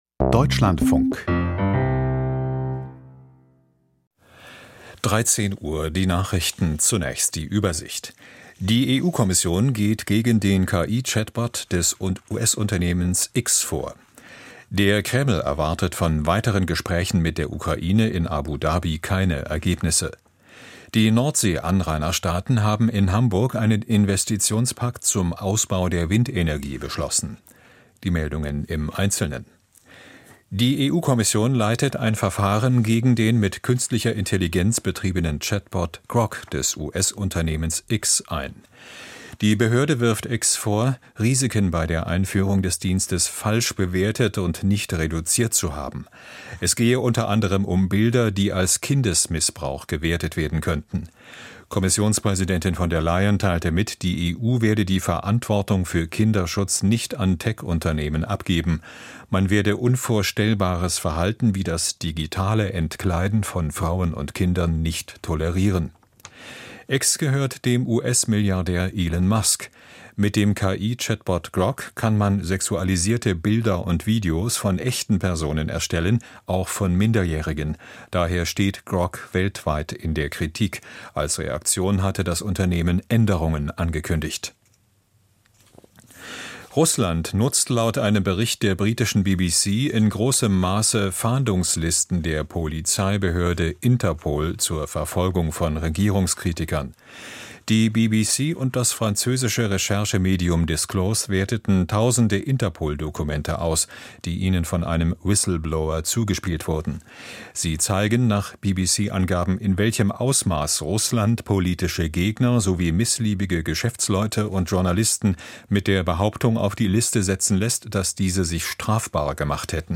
Die Nachrichten vom 26.01.2026, 13:00 Uhr
Aus der Deutschlandfunk-Nachrichtenredaktion.